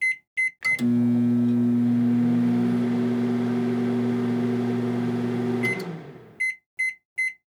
microwave-sound